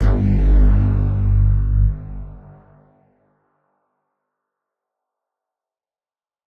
Bass_G_02.wav